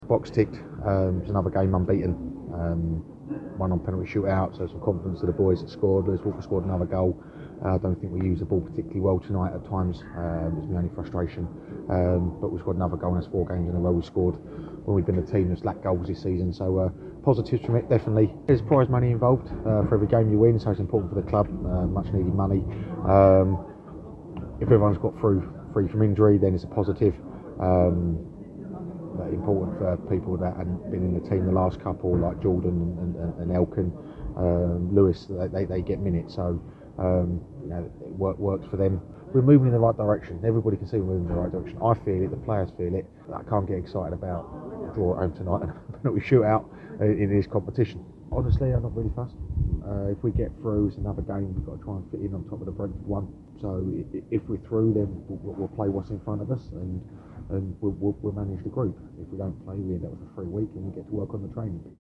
Manager Neil Harris spoke to us after the match.